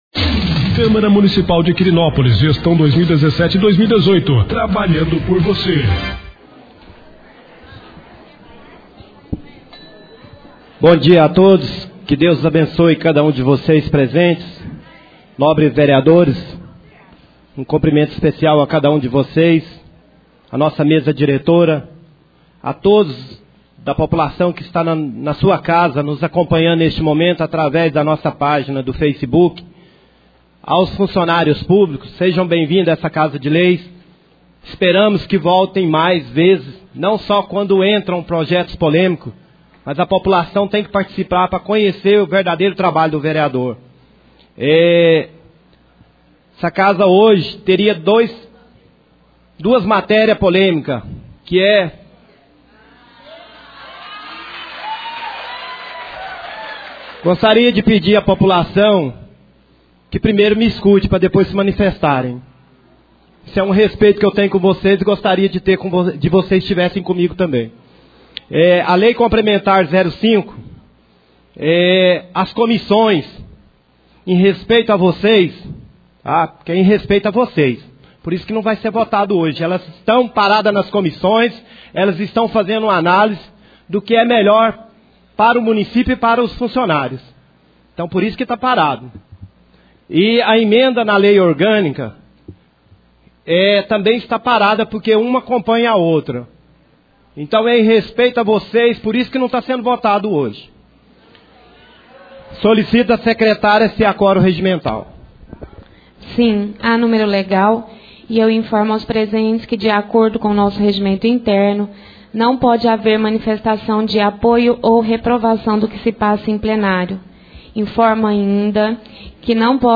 4° Sessão Ordinária do Mês de Dezembro 2017.